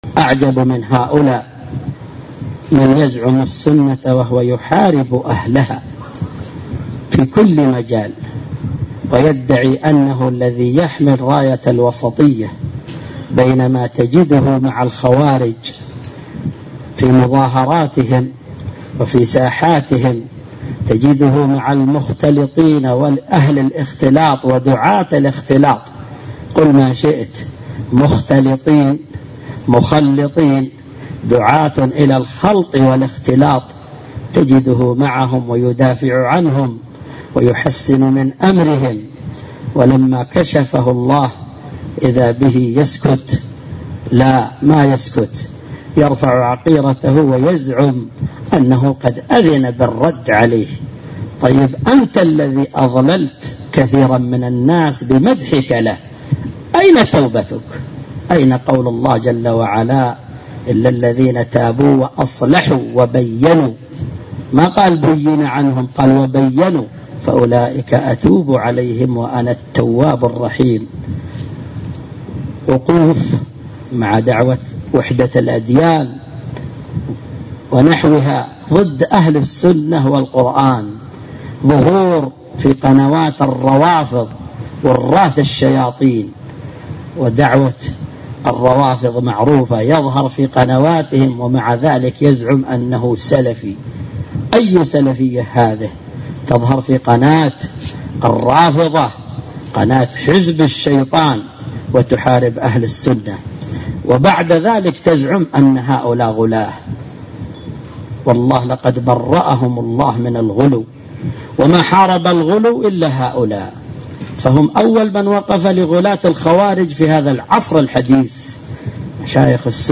مقتطف من اللقاء المفتوح